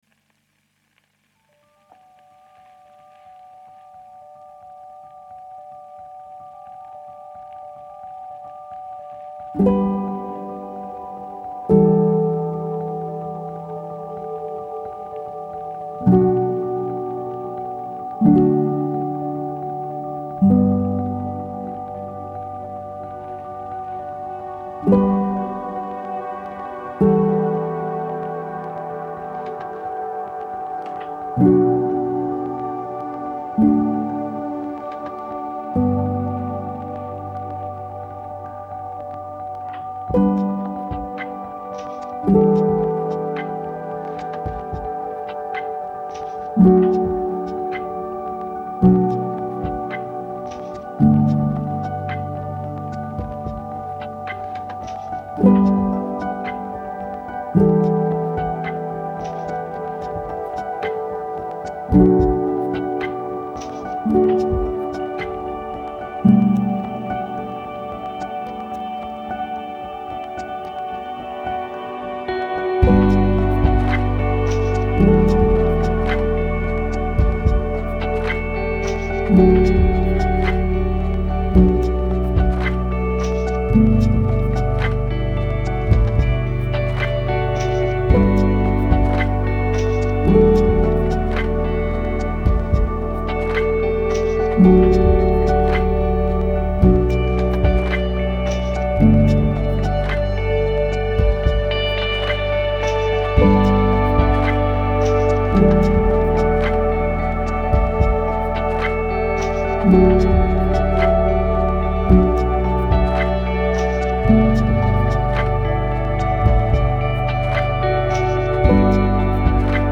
الهام‌بخش , امبینت , گیتار الکترونیک , موسیقی بی کلام